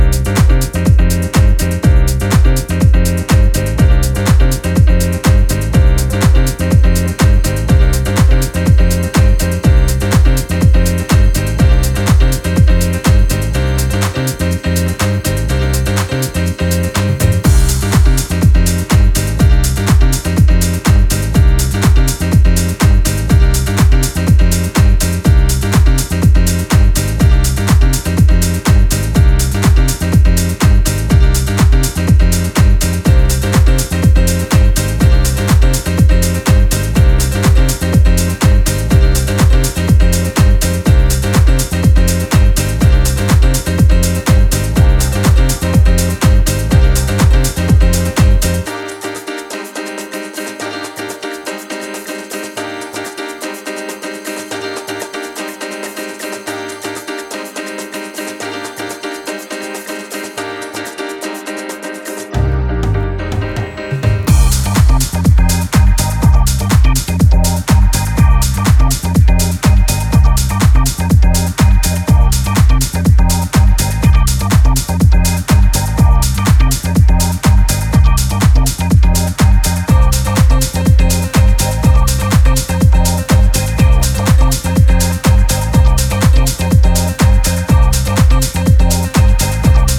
ピアノリフがオーセンティックなイタリアン・ハウスを思わせる